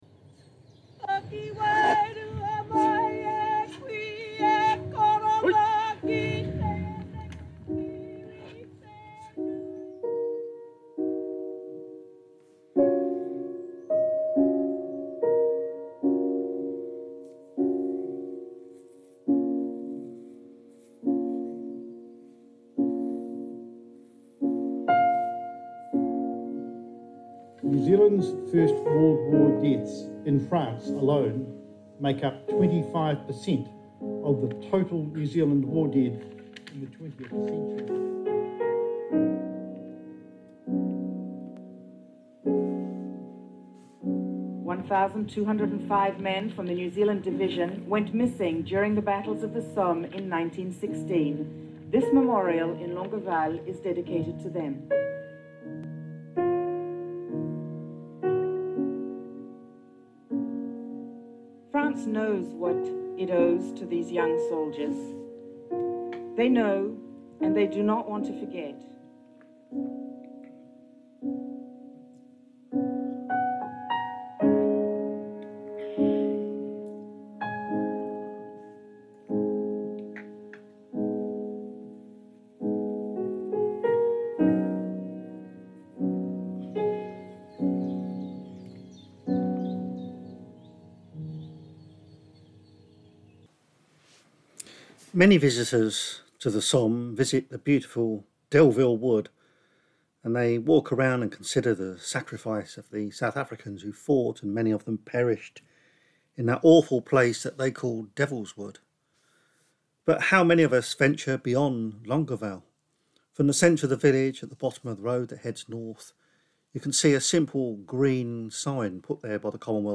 In this episode we follow in the footsteps of the New Zealand Expeditionary Force at Flers during the Battle of the Somme in September 1916. We look at what happened in this successful operation through the eyes of the soldiers who were there. We are joined, as we walk the battlefield, by the relatives of 2 Kiwis who died in the battle and get their thoughts on what their sacrifice means to them.